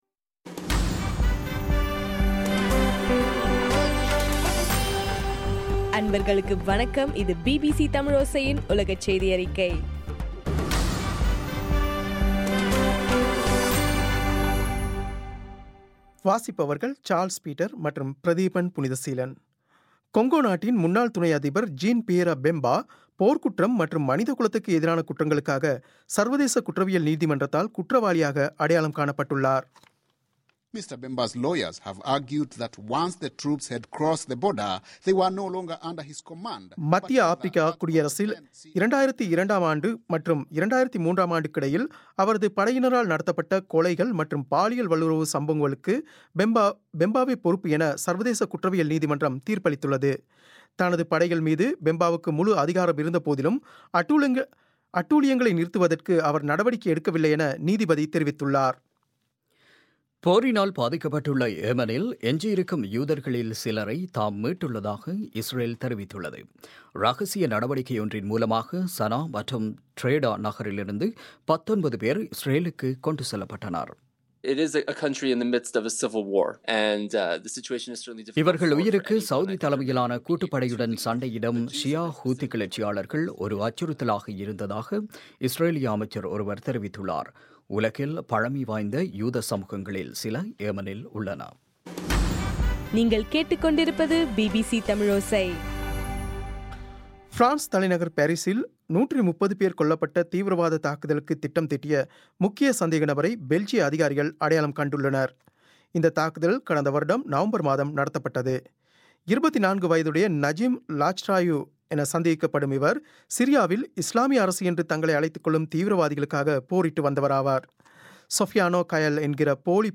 மார்ச் 21, 2016 பிபிசி செய்தியறிக்கை